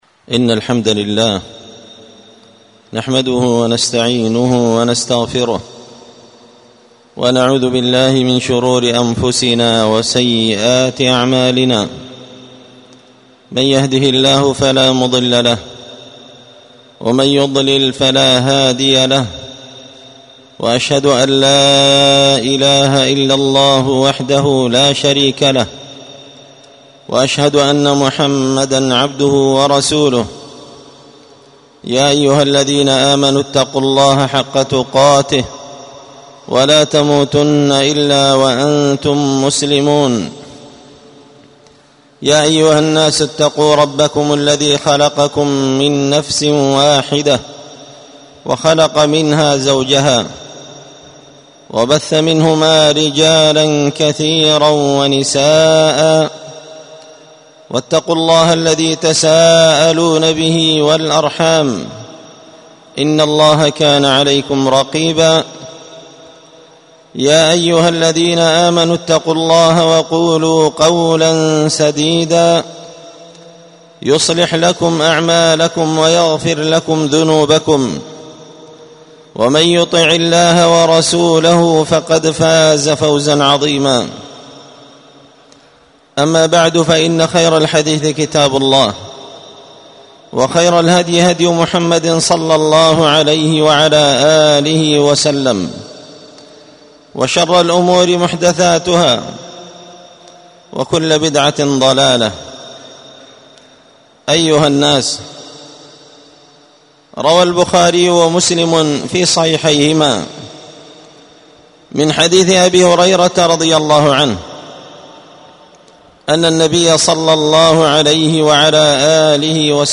ألقيت هذه الخطبة بدار الحديث السلفية بمسجد الفرقان قشن-المهرة-اليمن